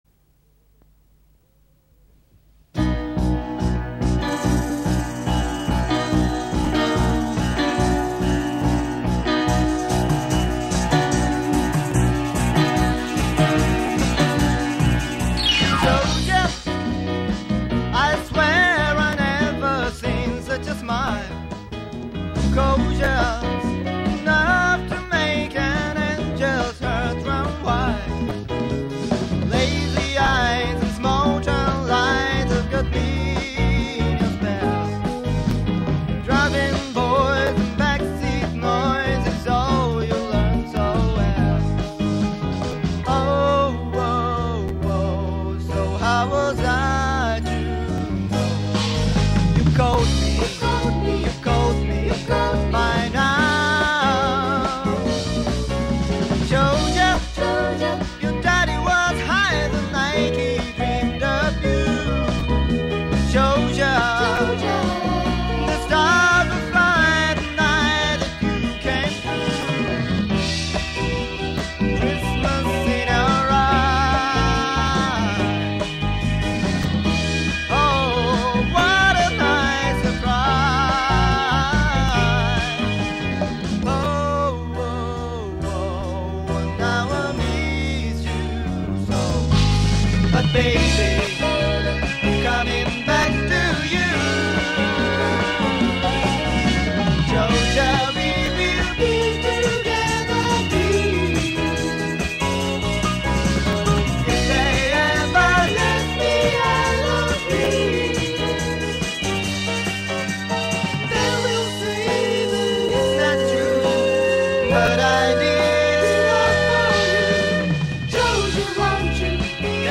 このページでは、過去のライブ音源や映像を、ダウンロード頂けます。
音羽スタジオ